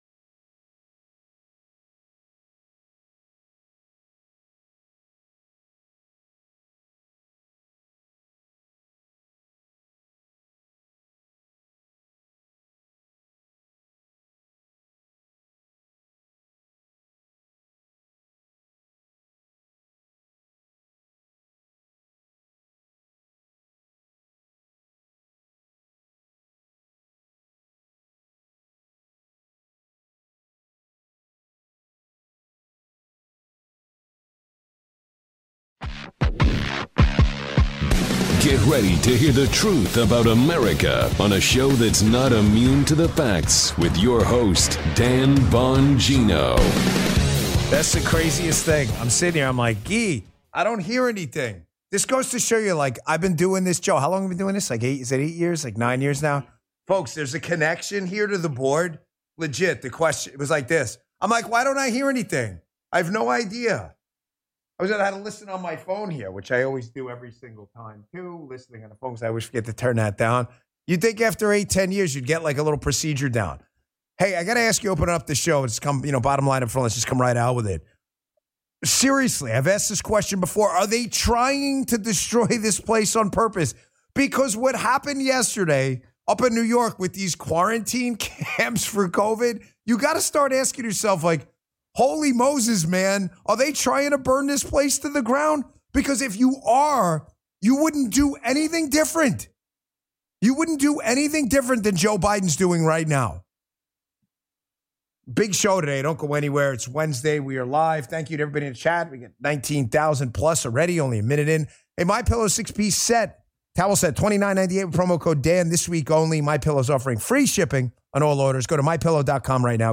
➡ The provided text is a transcript from a radio show by Dan Bongino, where he discusses his concern over potential COVID quarantine camps in New York, raises questions about Democrats' intent to 'ruin the United States', and advises listeners to leave New York State.